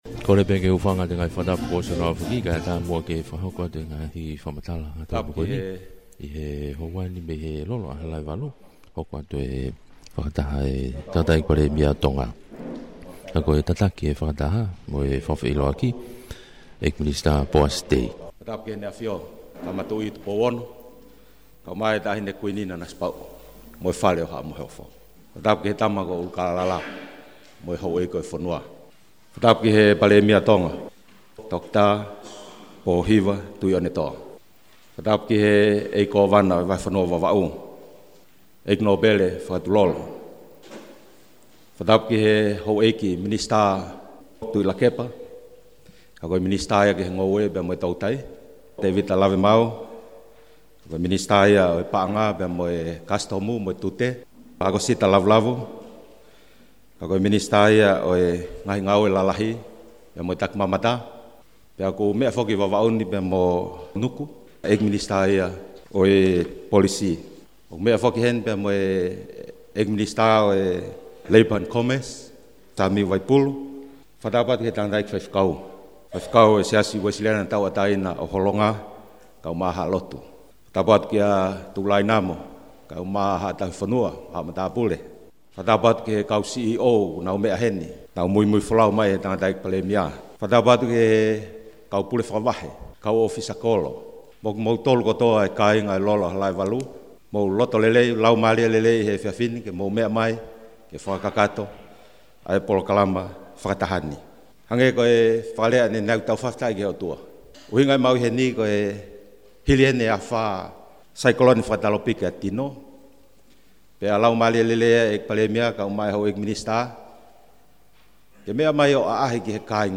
An audio of the Prime Minister’s meeting in Vava’u was provided by the Prime Minister’s office and transcribed and translated into English by Kaniva News.